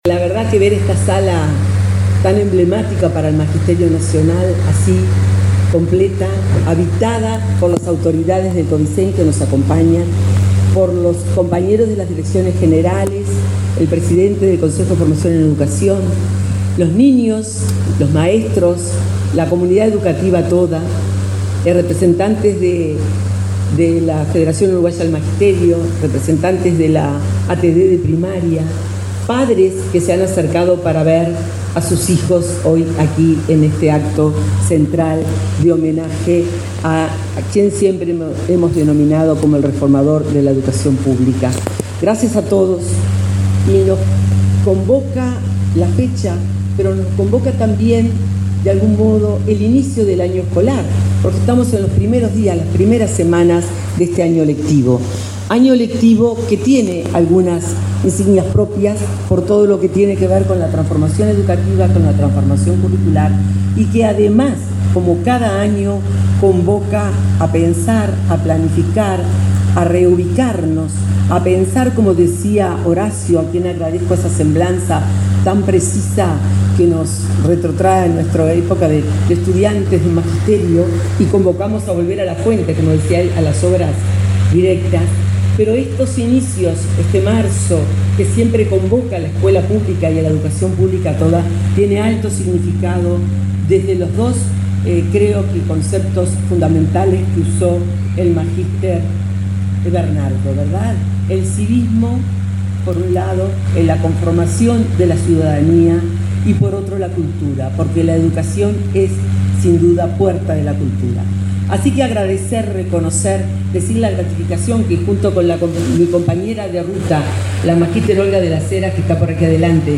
Palabras de autoridades de la ANEP
Palabras de autoridades de la ANEP 20/03/2023 Compartir Facebook X Copiar enlace WhatsApp LinkedIn La directora de Primaria, Graciela Fabeyro, y la consejera Dora Graziano participaron en el acto realizado por esta dependencia este lunes 20 en Montevideo, en homenaje a José Pedro Varela, en el 178.° aniversario de su nacimiento.